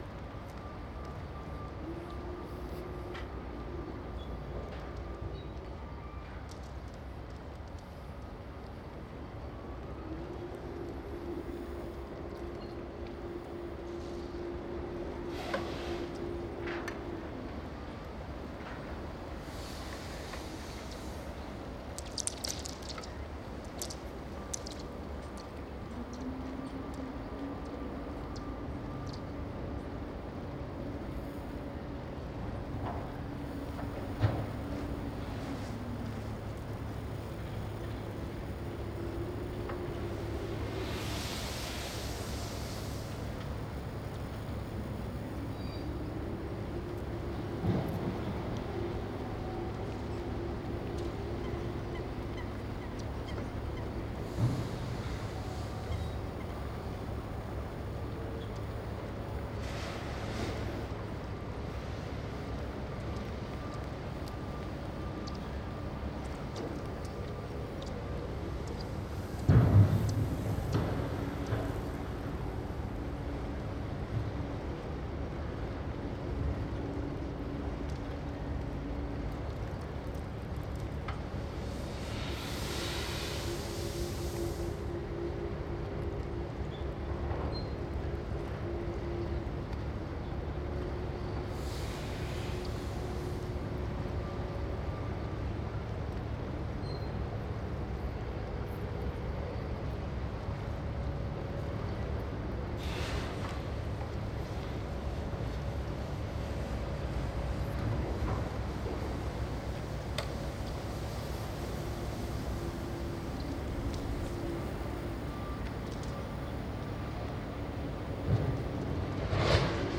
Cranes unloading ship, water
Cranes unloading gravel from ship, harbour ambience, splashing water. Sony MS microphone
Adress: Saturnuskade, Binckhorst, Laak, The Hague, South Holland, Netherlands, 2516 AG, Netherlands
binckharbourshipunloadingMS.mp3